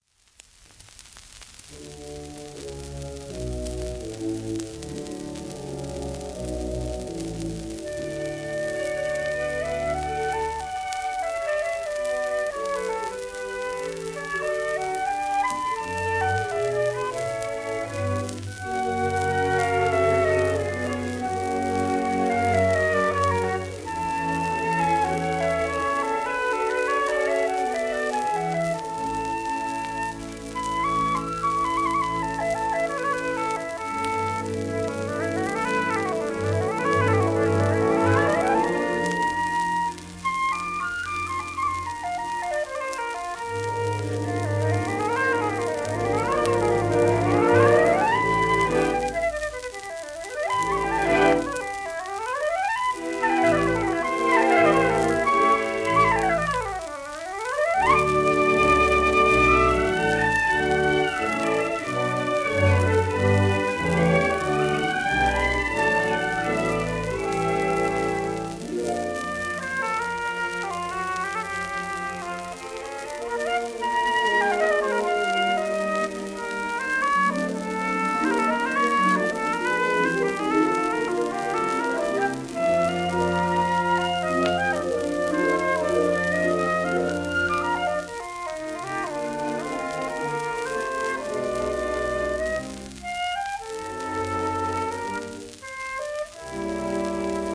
w/オーケストラ
盤質A-/B+ *長いキズ、小キズ、薄いスレ[キズは音への影響少ない]
ニュージーランド生まれのオーストラリア人フルート奏者。